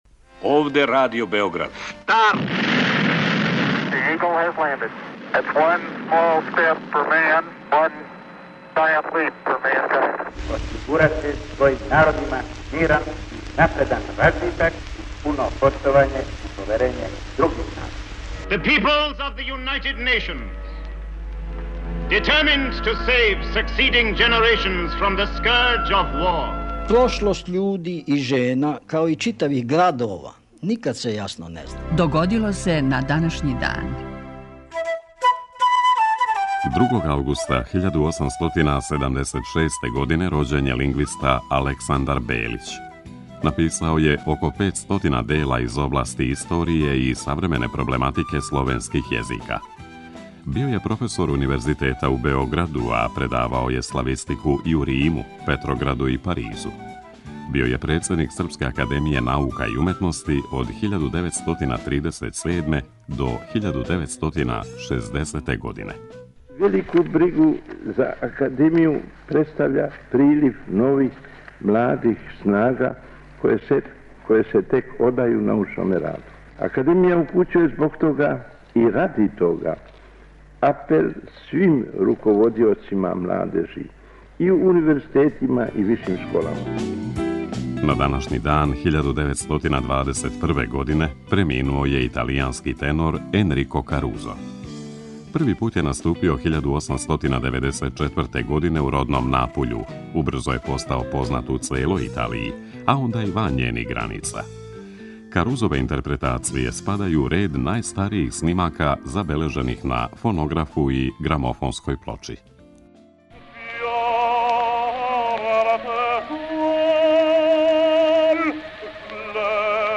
У петотоминутном прегледу, враћамо се у прошлост и слушамо гласове људи из других епоха.